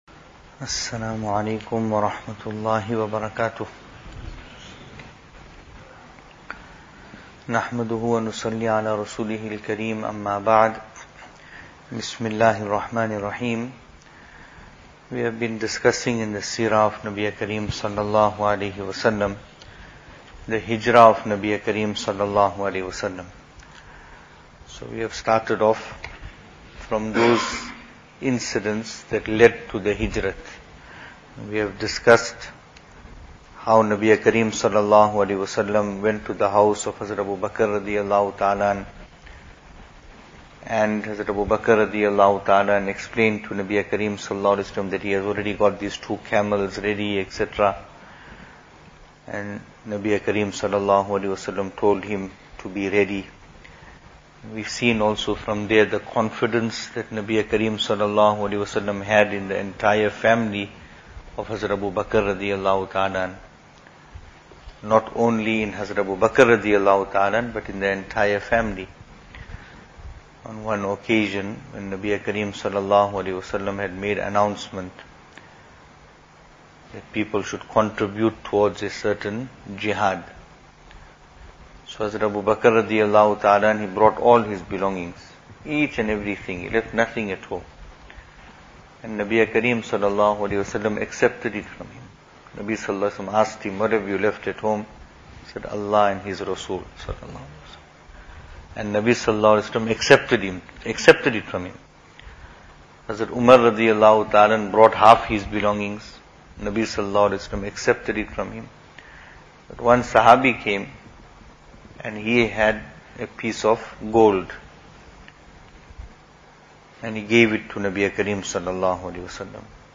Venue: Masjid Taqwa, Pietermaritzburg | Series: Seerah Of Nabi (S.A.W)
Service Type: Majlis